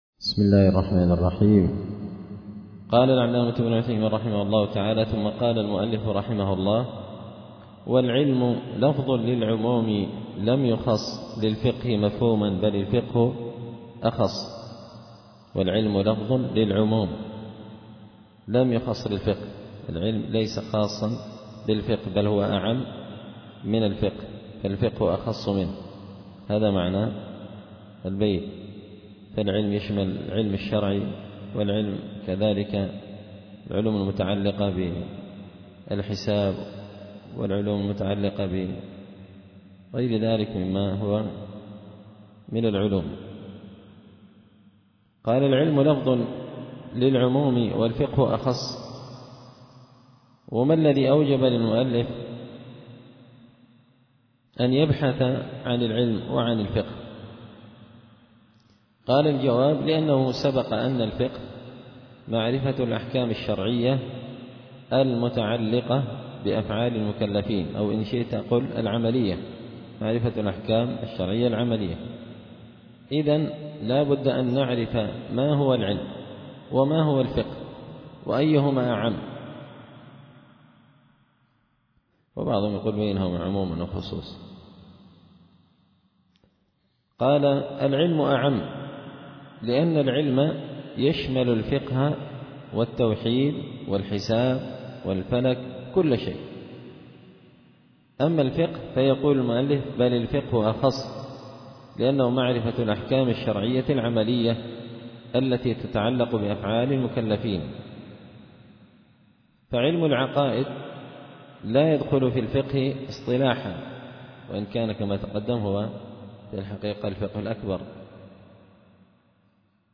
التعليقات على نظم الورقات ـ الدرس 7
دار الحديث بمسجد الفرقان ـ قشن ـ المهرة ـ اليمن